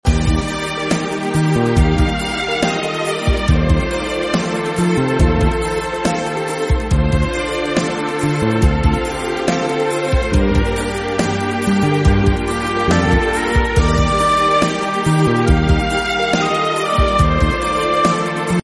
電子オルガン